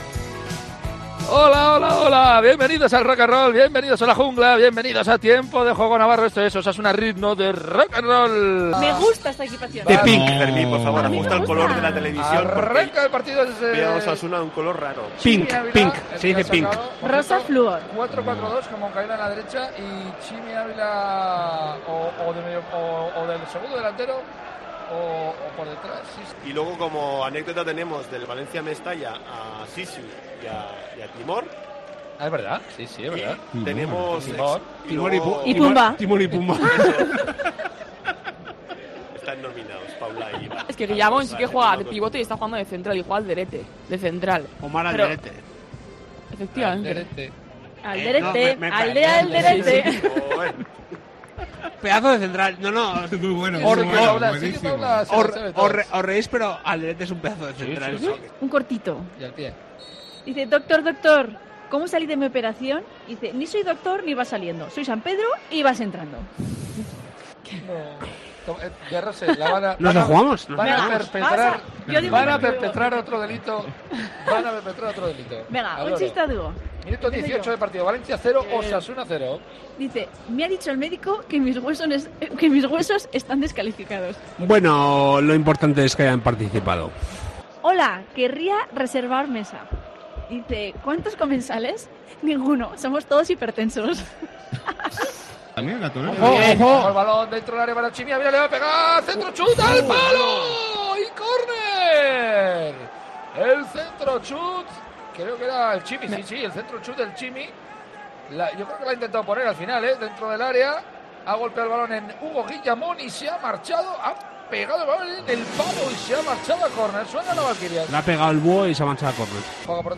AUDIO: Los mejores momentos de Tiempo de Juego Navarro-Osasuna a ritmo de Rock & Roll en la victoria de Osasuna en Mestalla